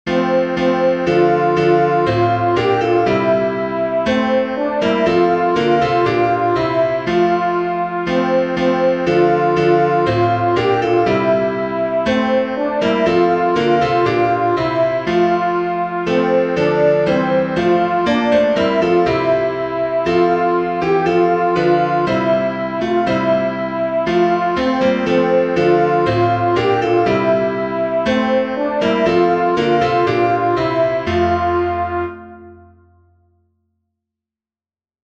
Alto
llanfair-alto.mp3